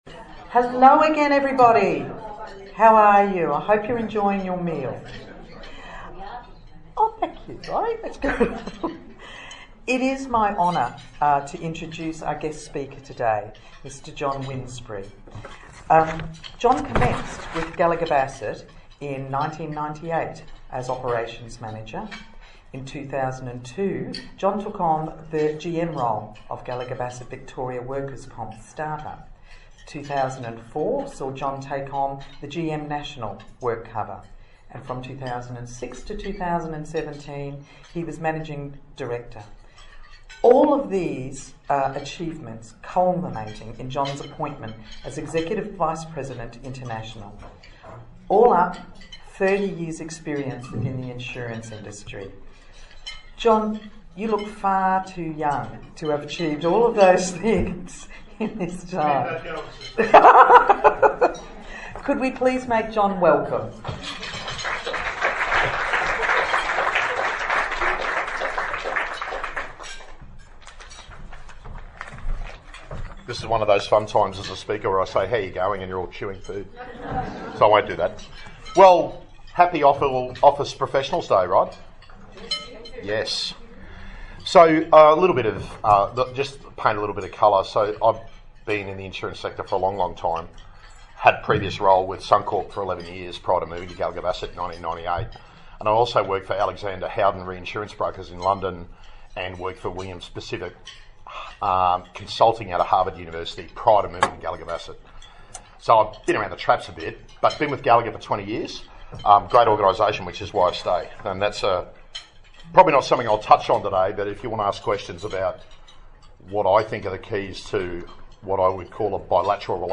– All the do’s and don’ts – what works and what doesn’t. – Words of wisdom you won’t hear anywhere else – in one compelling lunchtime address.